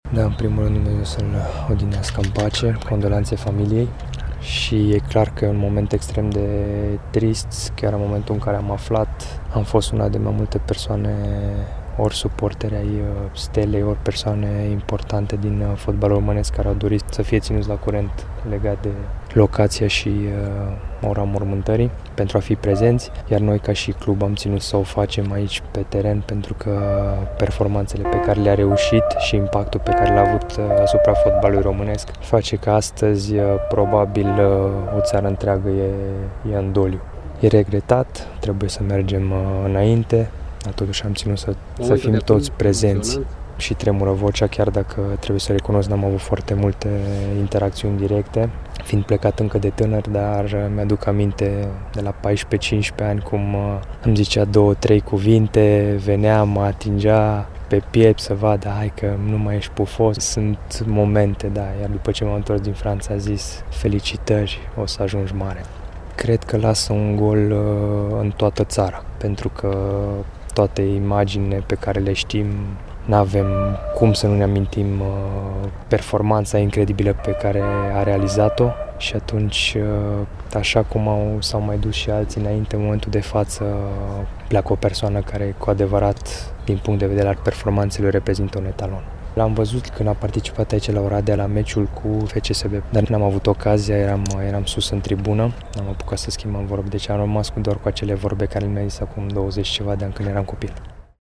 Pe Stadionul Iuliu Bodola din Oradea, liniștea a luat locul aplauzelor.
a vorbit cu vocea tremurândă despre pierderea unei legende: